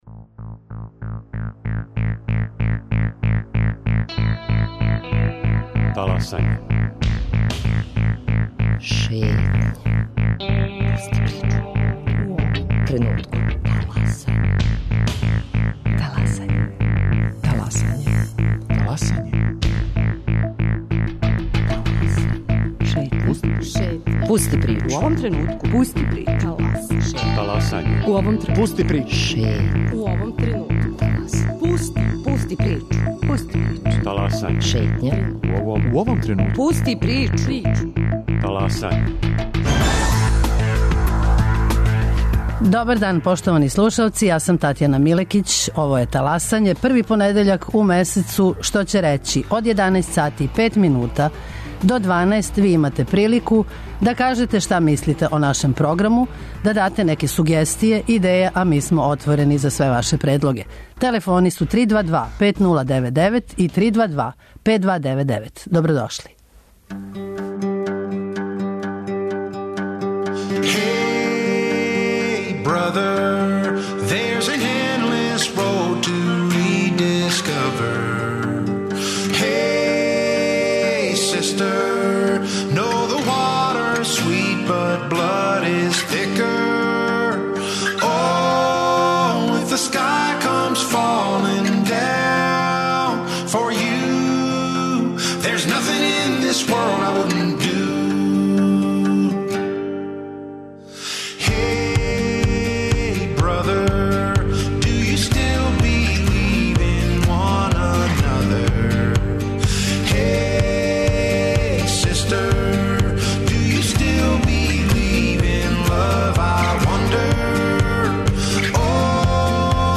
Као и сваког првог понедељка у месецу, и данас вам пружамо прилику да 'таласате' заједно са нама. Ви причате, сугеришете, коментаришете - ми слушамо!